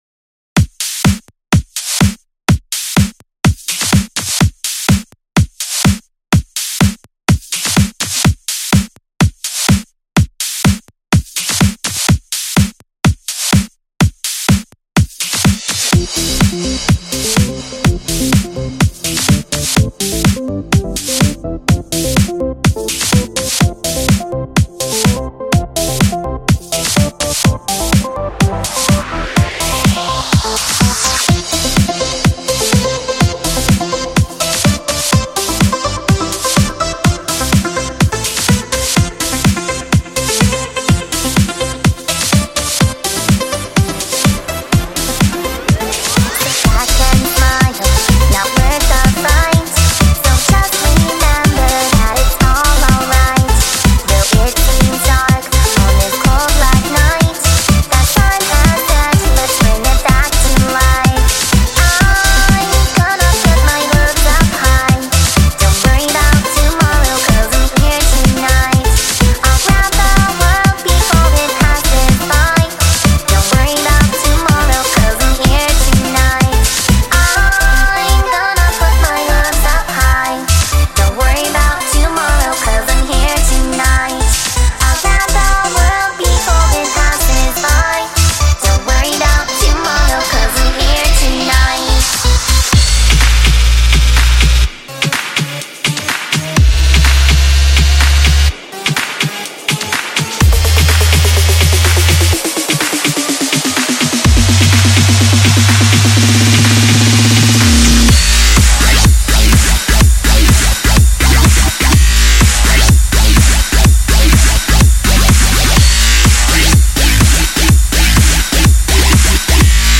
Remix i created in summer 2013.